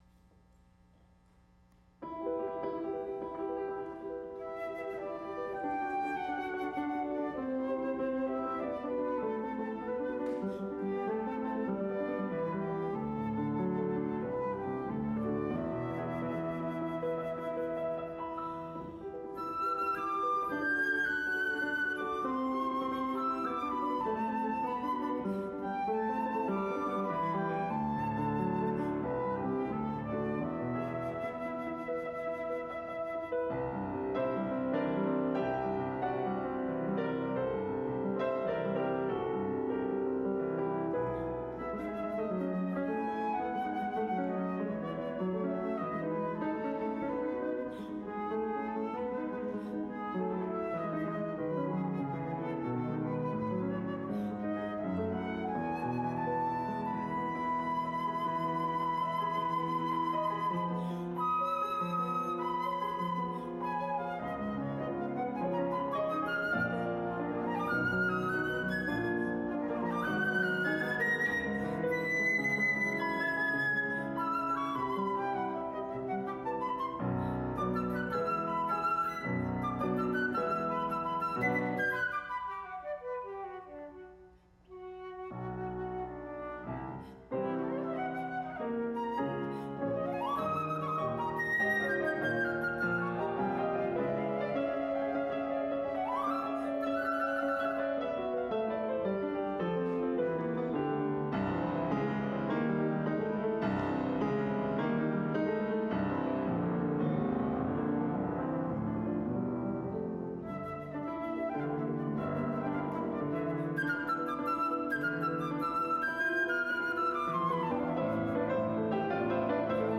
for Flute and Piano